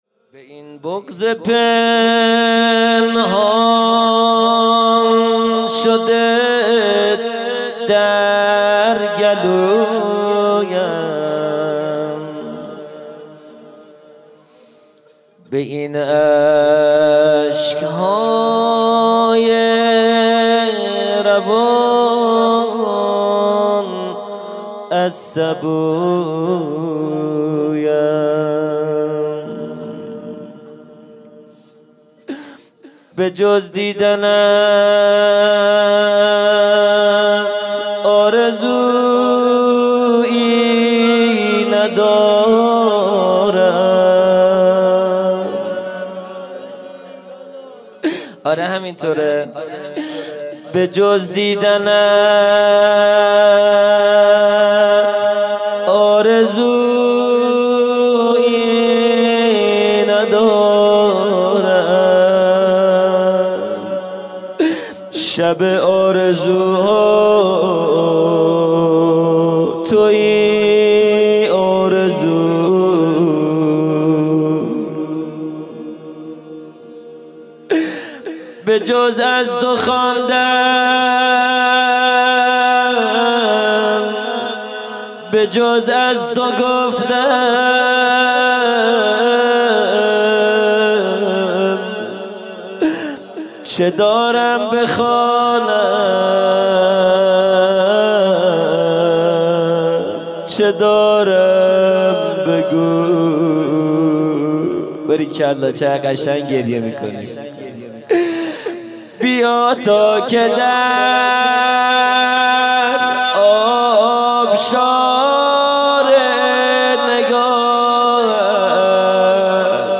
خیمه گاه - هیئت بچه های فاطمه (س) - روضه | به این بغض پنهان شده در گلویم
جلسۀ هفتگی ( ویژه برنامۀ شب لیلة الرغائب )